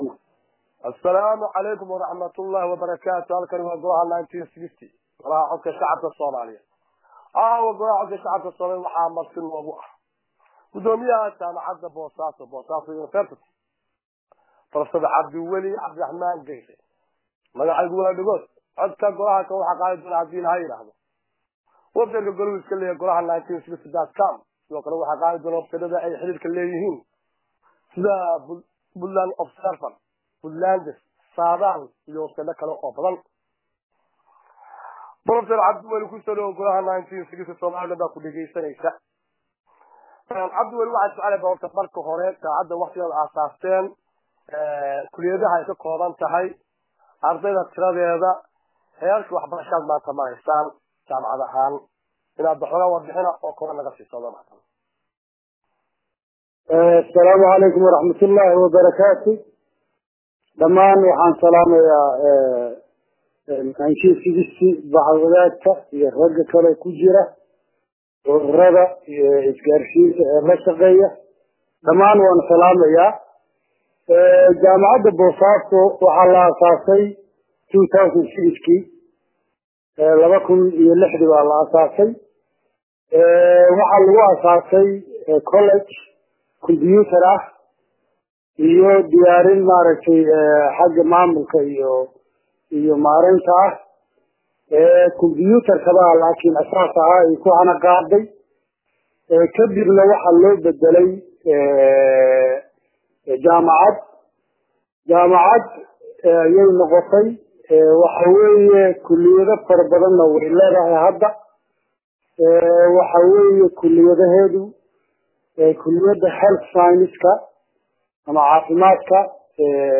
Waraysigan